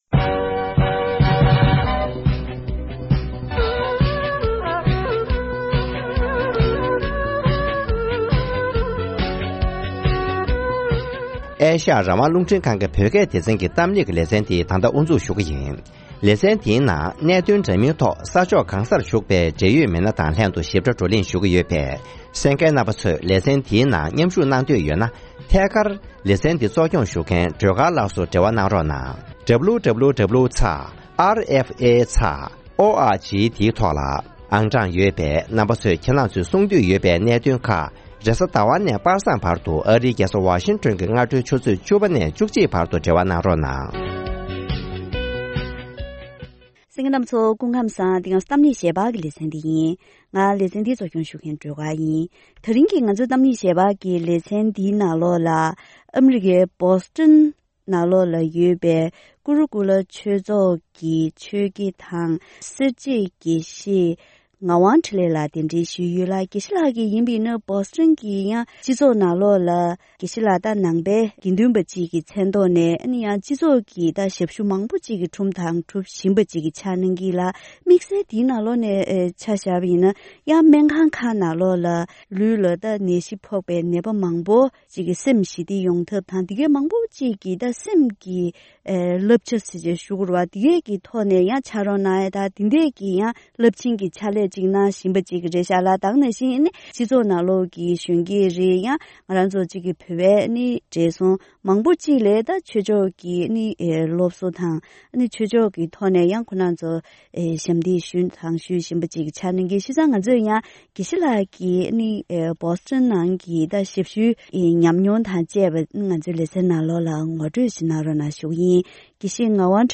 གཏམ་གླེང་ཞལ་པར་ལེ་ཚན།